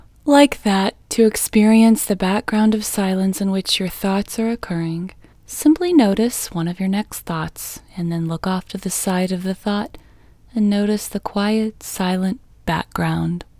LOCATE IN English Female 16